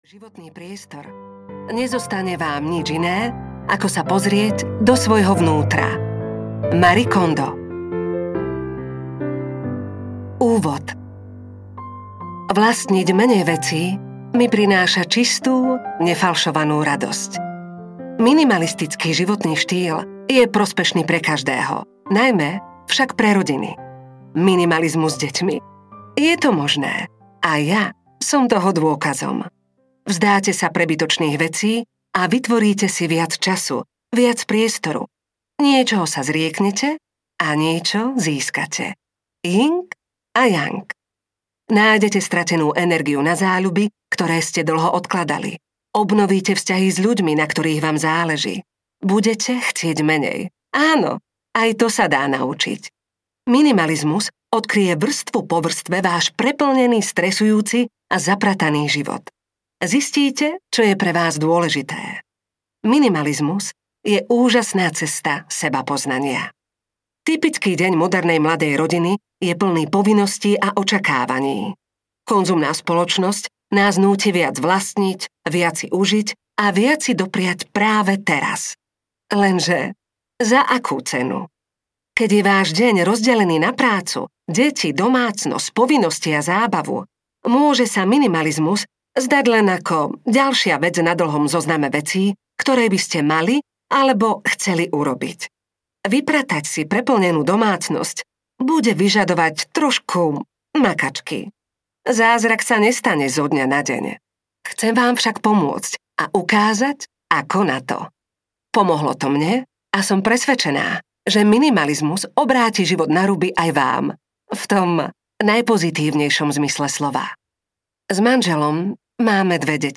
Minimalistický domov audiokniha
Ukázka z knihy